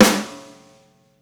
• Sparkle Steel Snare Drum G Key 433.wav
Royality free acoustic snare tuned to the G note. Loudest frequency: 2091Hz
sparkle-steel-snare-drum-g-key-433-46I.wav